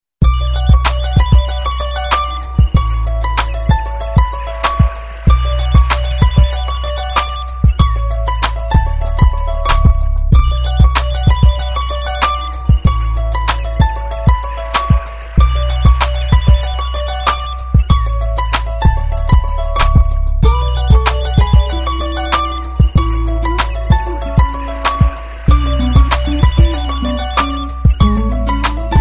effect-ring-ring_24706.mp3